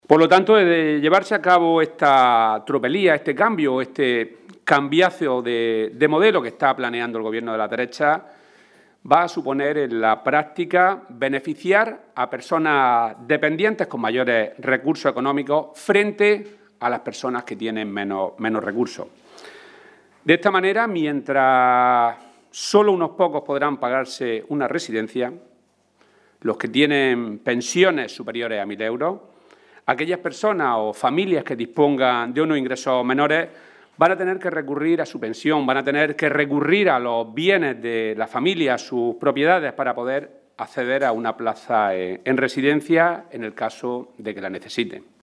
hoy, en rueda de prensa